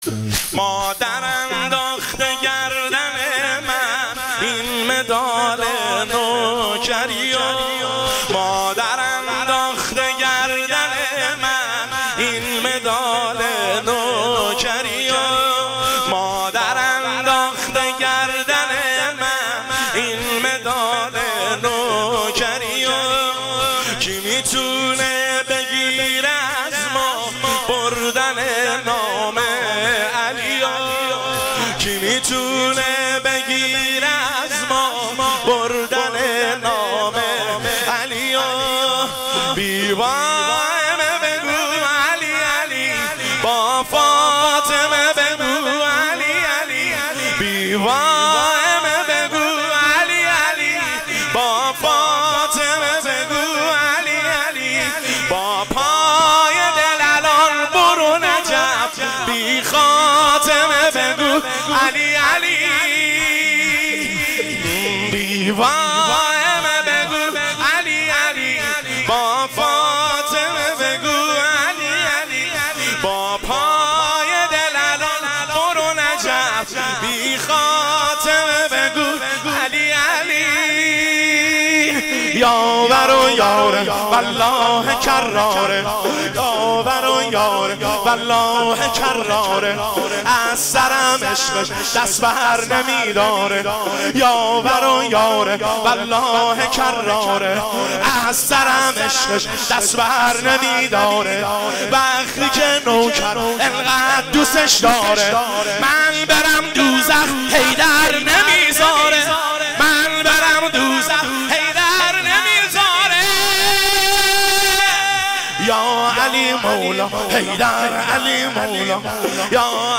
شور شب دوم فاطمیه دوم 1404
هیئت بین الحرمین طهران
دانلود با کیفیت LIVE